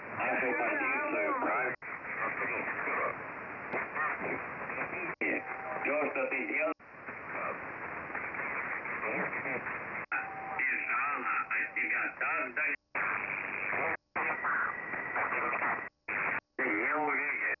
гармошка на 14100 от бардака на 40м